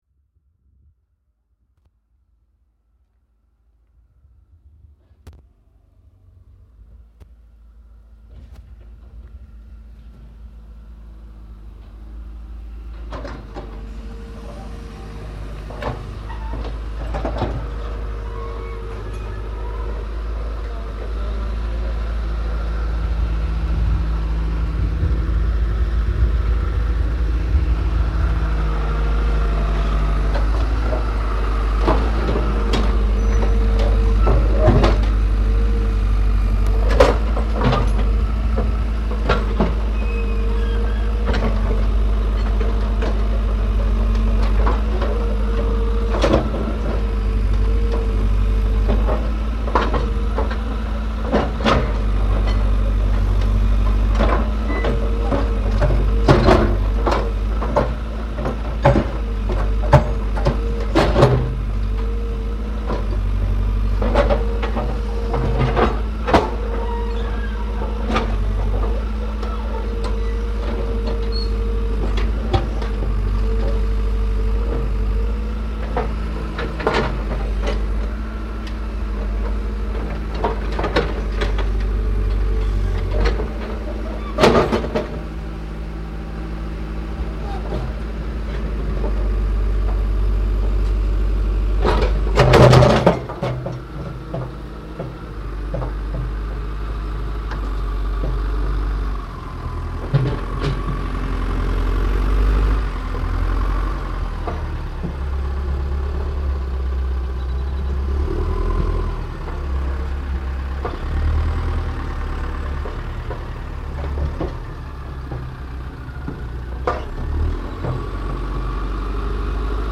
Caterpillar
Este es el sonido cotidiano, desde hace unos meses, de una de las colonias de Tuxtla Gutierrez, en la que están pavimentando una avenida.
Lugar de la grabación: Oriente Norte de Tuxtla Gutierrez, Chiapas; Mexico.
Equipo: Minidisc NetMD MD-N510, micrófono de construcción casera ( más info ) Fecha: 2007-06-08 11:58:00 Regresar al índice principal | Acerca de Archivosonoro